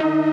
Freq-lead33.ogg